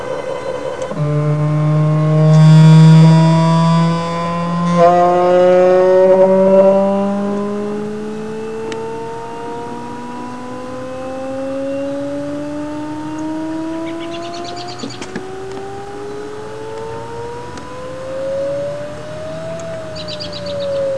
Click for the sound of a half-stepping azimuth slew (452k WAV)